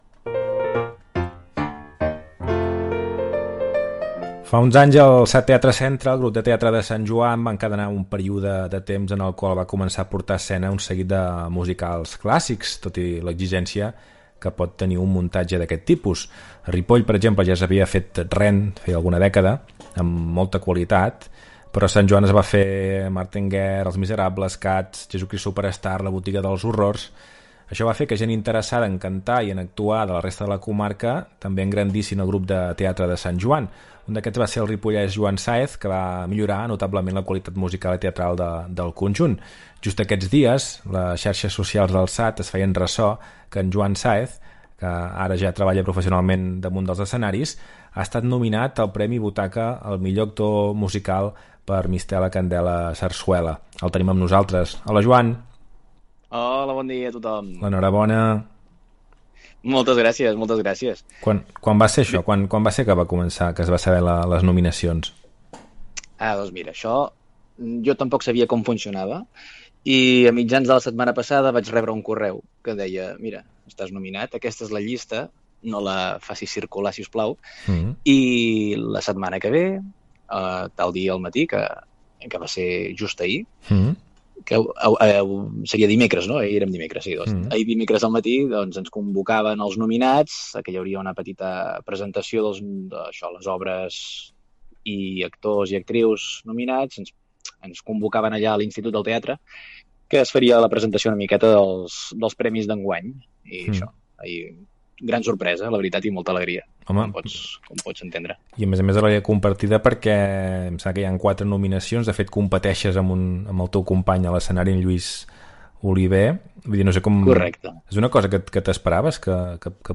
Entrevista
Info-entreteniment